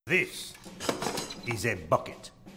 The Spy